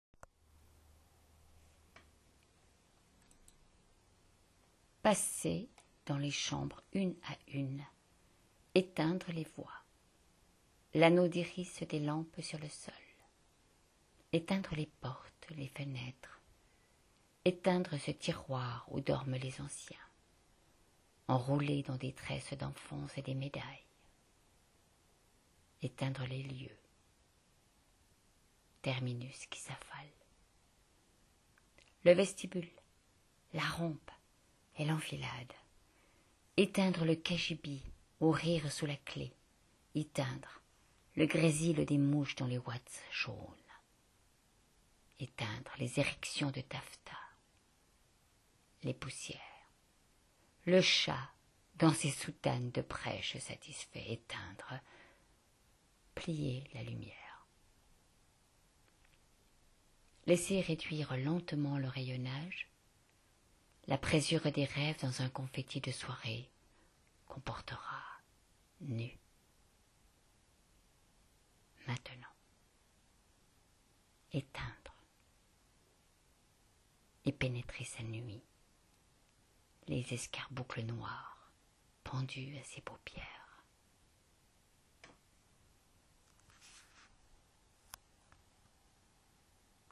C’est précieux, une voix.